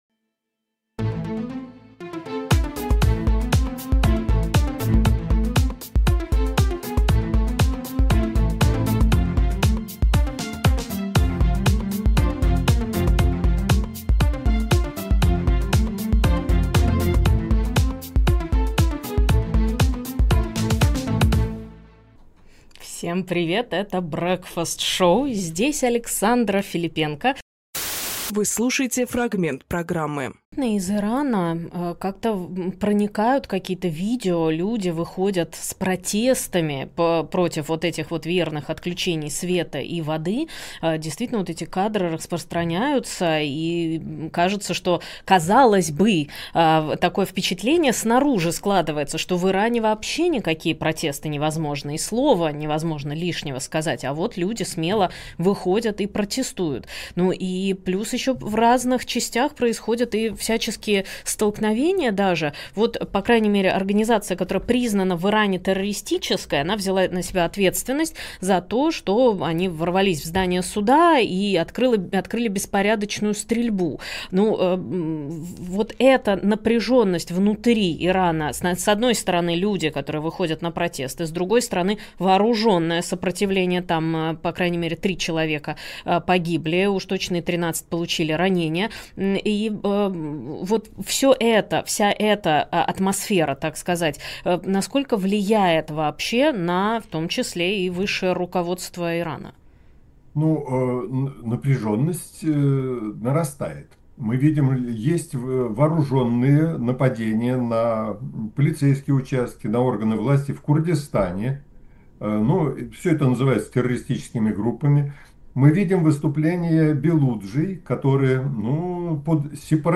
Фрагмент эфира от 27.07.25